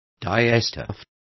Also find out how tinte is pronounced correctly.